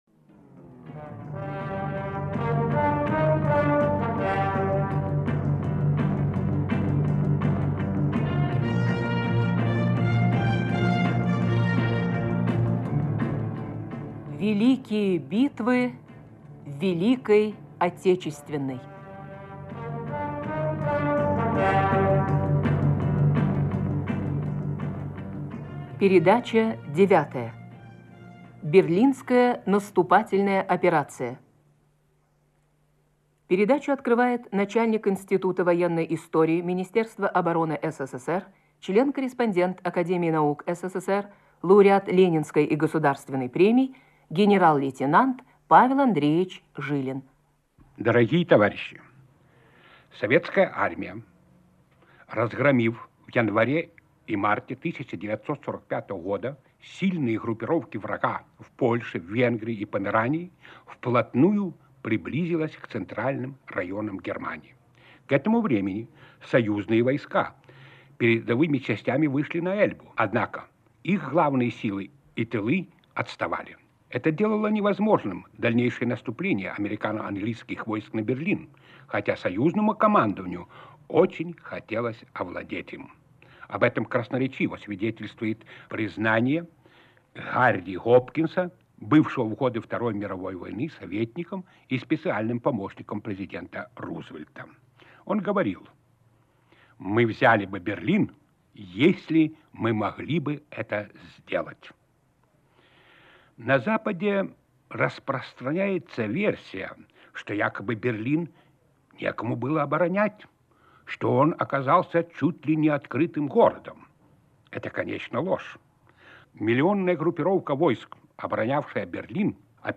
Предлагаем вашему вниманию архивную запись радиопрограммы, посвященной Берлинской наступательной операции.